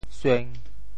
sueng1.mp3